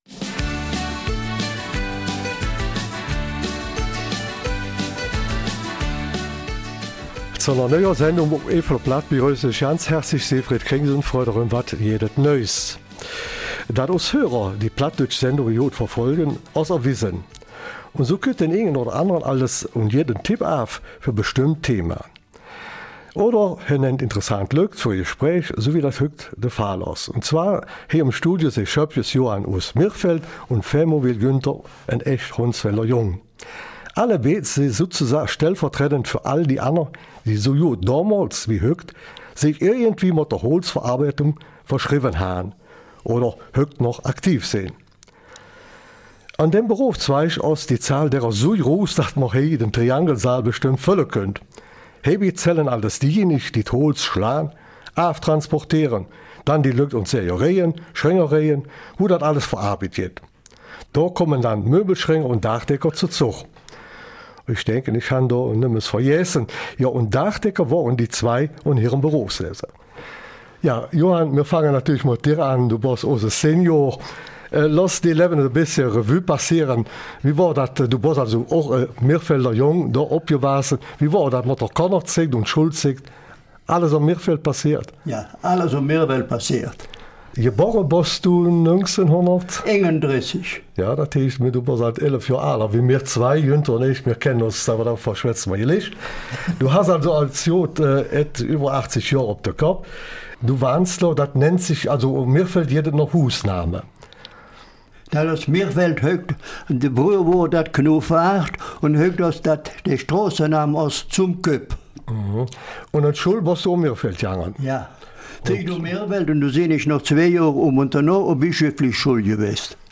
Eifeler Mundart: Der Beruf des Bauschreiners